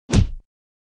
AK_Impact.wav